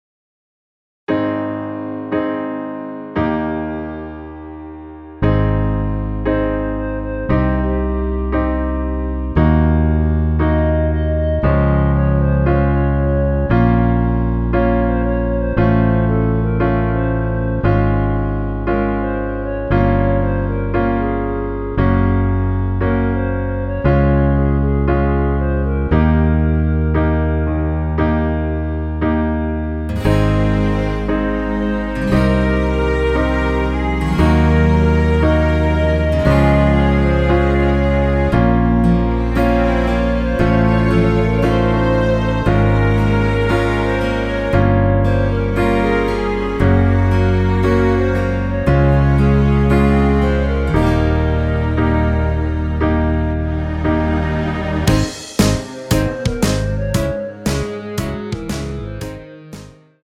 원키에서(-1)내린 멜로디 포함된 MR입니다.(미리듣기 확인)
Ab
앞부분30초, 뒷부분30초씩 편집해서 올려 드리고 있습니다.
중간에 음이 끈어지고 다시 나오는 이유는